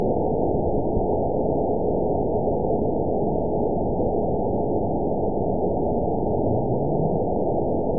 event 919866 date 01/26/24 time 16:42:52 GMT (1 year, 11 months ago) score 9.49 location TSS-AB03 detected by nrw target species NRW annotations +NRW Spectrogram: Frequency (kHz) vs. Time (s) audio not available .wav